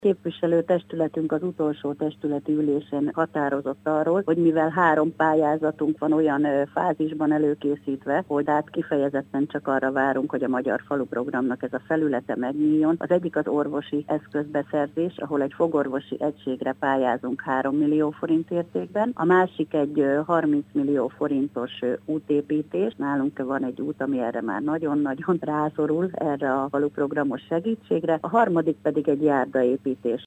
Nagy Andrásné polgármestert hallják.